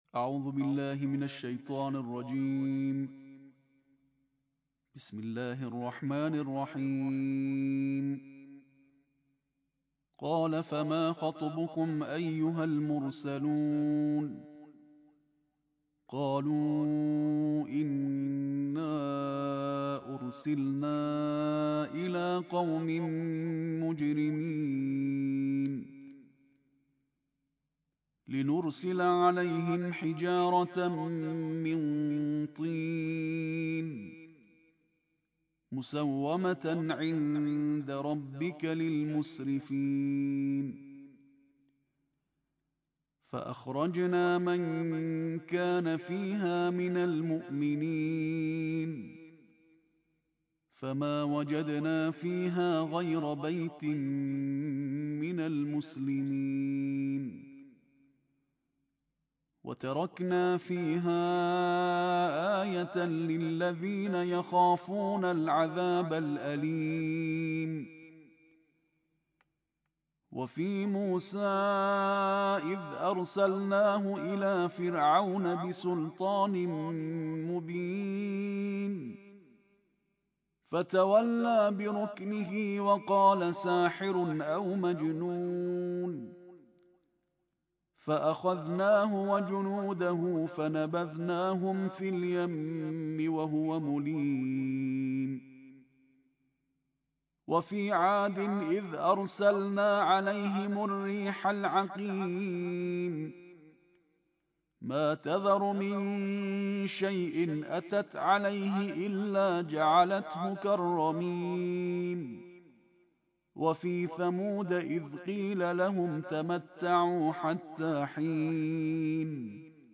ترتیل جزء 27 قرآن
این تلاوت در سال‌های ۱۳۹۹ و ۱۴۰۰ در استودیوی شورای عالی قرآن ضبط شده و سال ۱۴۰۱ در حاشیه هفدهمین نشست تخصصی استادان، قاریان و حافظان ممتاز قرآن کریم رونمایی شده است.
از ویژگی‌های این تلاوت که برای اولین بار است در رسانه منتشر می‌شود، باید به کیفیت بالای صوت قاری و کیفیت فنی ضبط آن اشاره کرد.